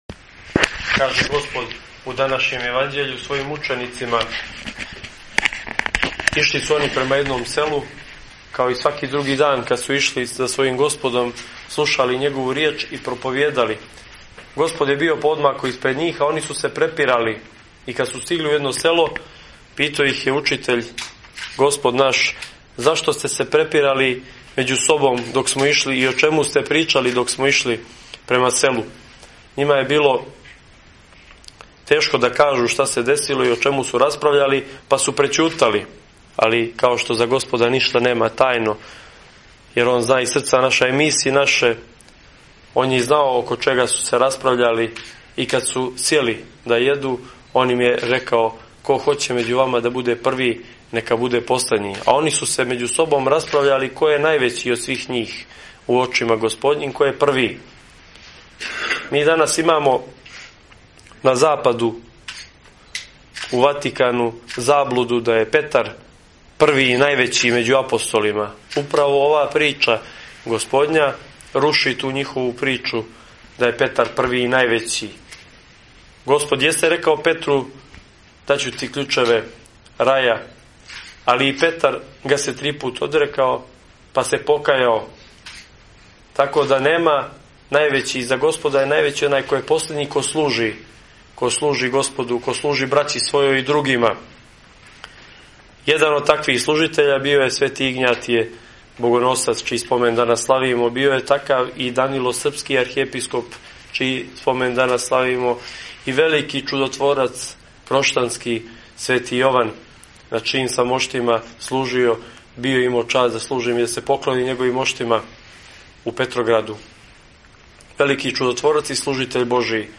Бесједа 2. јануара 2015.mp3